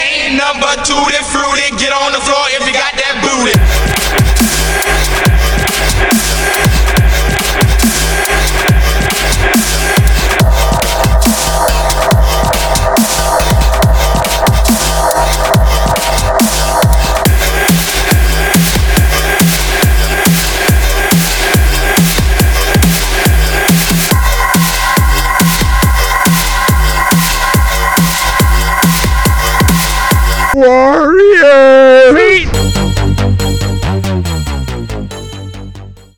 • Качество: 320, Stereo
громкие
жесткие
мощные
Electronic
EDM
мощные басы
Dubstep